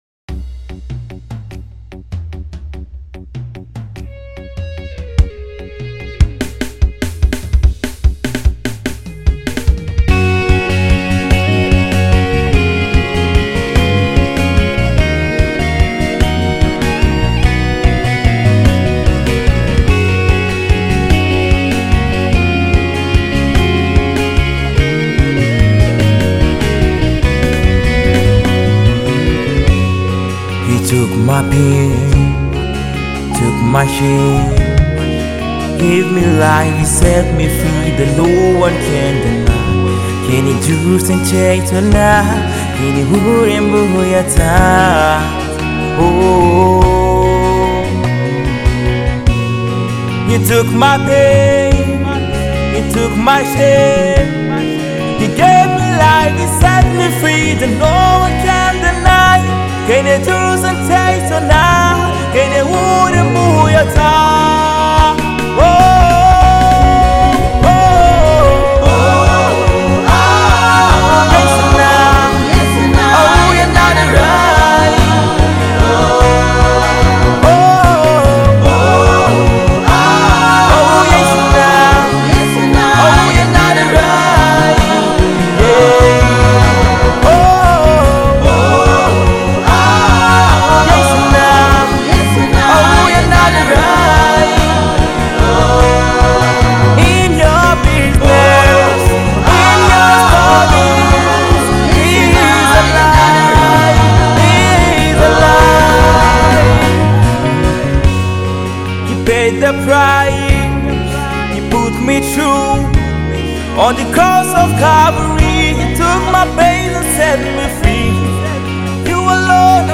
Gospel song
is an acoustic guitarist, gospel artist,singer